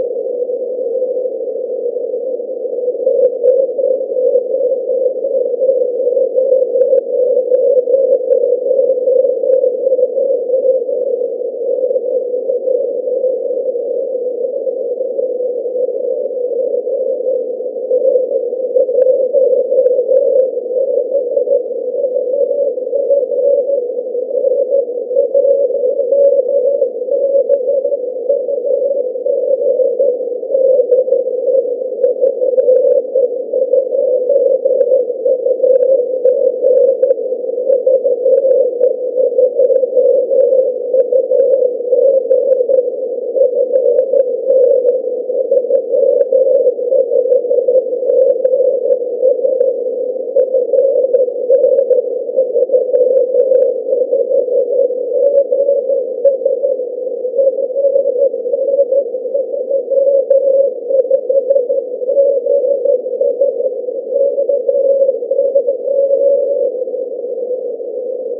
It might be just rain scatter with very little spreading.
audio clip demonstrating the change in tone when I moved my dish.